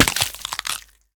Minecraft Version Minecraft Version latest Latest Release | Latest Snapshot latest / assets / minecraft / sounds / mob / turtle / egg / egg_break1.ogg Compare With Compare With Latest Release | Latest Snapshot
egg_break1.ogg